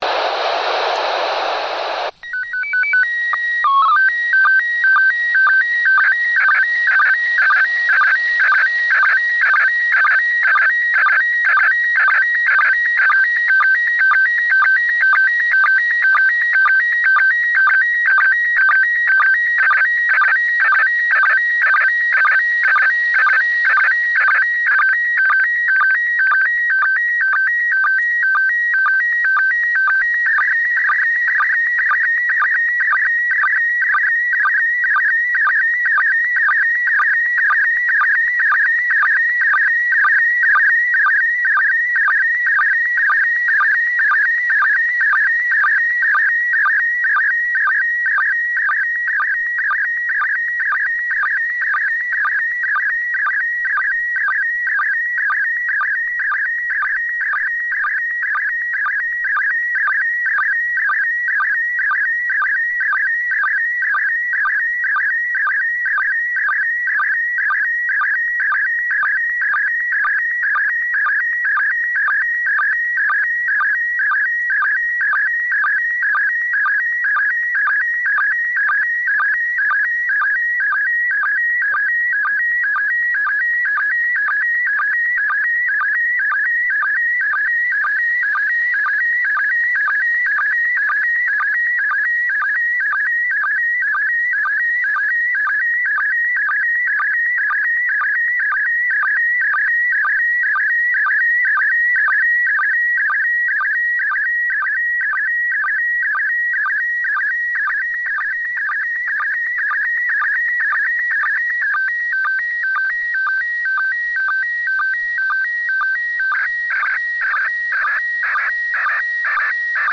I included the sound files for each image so you can hear the SSTV tones that were transmitted.